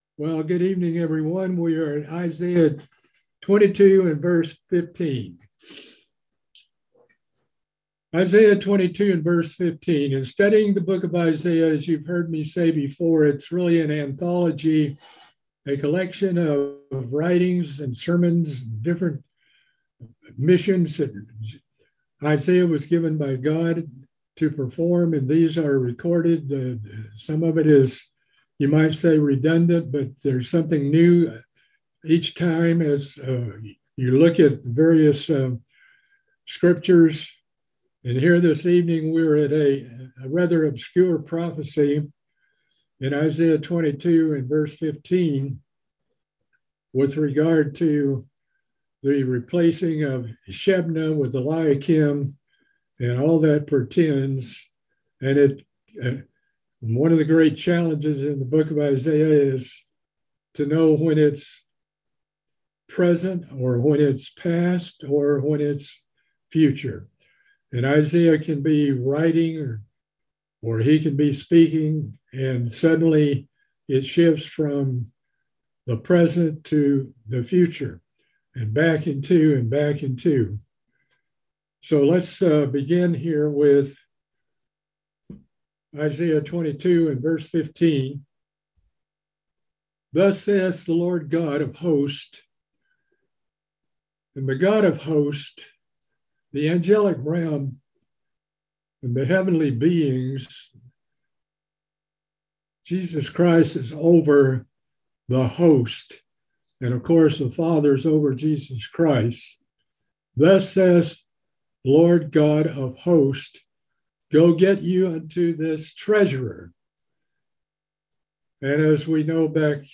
Book of Isaiah Bible Study - Part 17